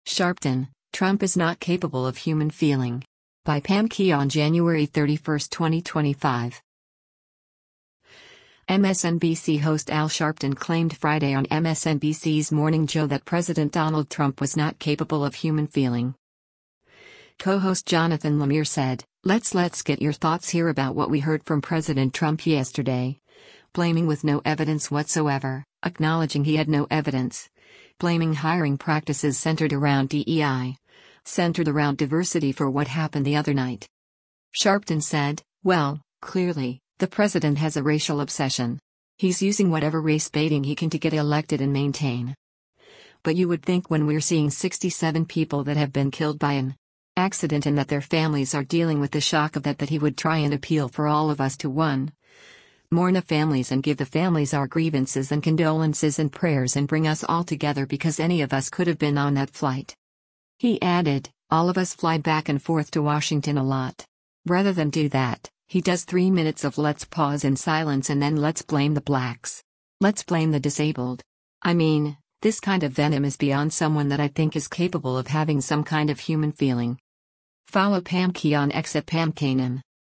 MSNBC host Al Sharpton claimed Friday on MSNBC’s “Morning Joe” that President Donald Trump was not capable of “human feeling.”